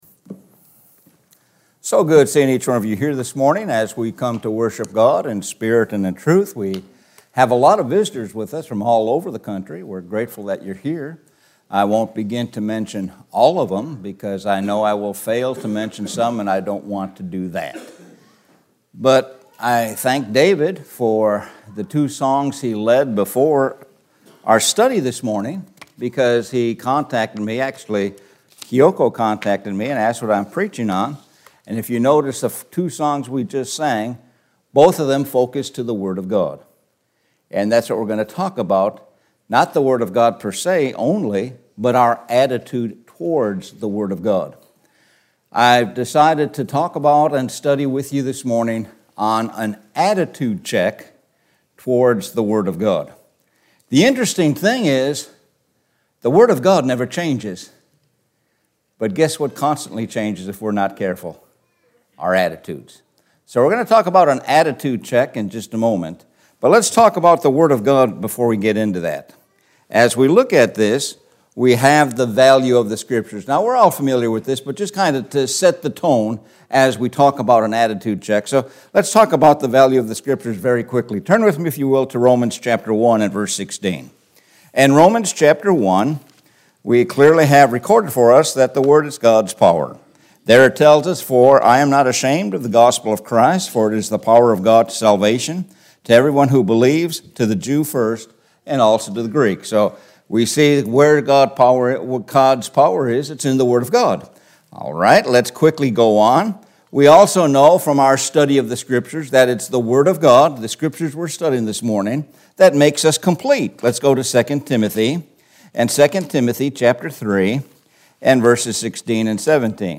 Sun AM Bible Sermon – What is your Attitude towards Gods word